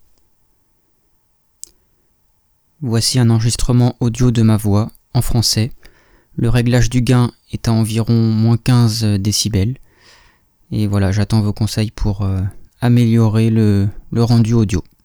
I want to create small podcasts, I bought a Rode NT-USB for that.
^^ I send you my recording: /uploads/default/original/3X/4/9/494d33b044d8a45fa936552e04d964bfc444c154.wav I’m in a 10m² room with no extraneous noise, apart from the computer fan, but I put myself at a good distance to minimize the noise.